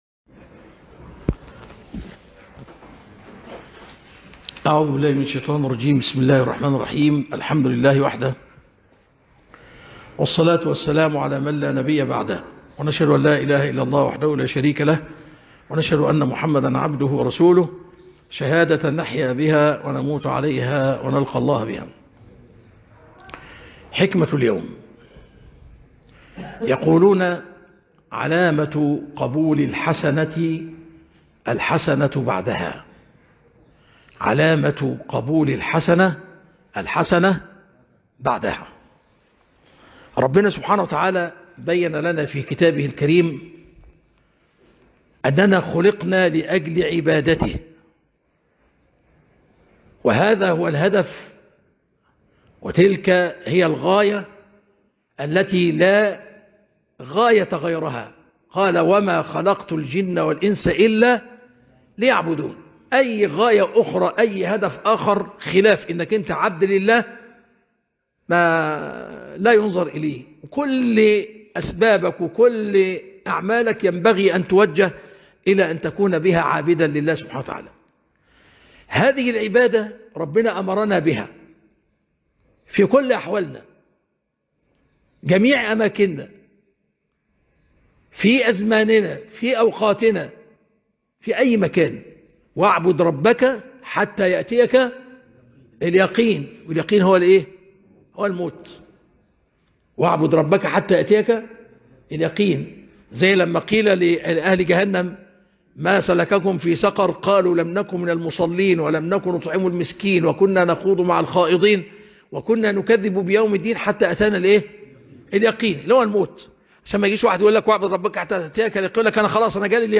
خاطرة حول ( علامة قبول الحسنة الحسنة بعدها) - الشيخ طلعت عفيفى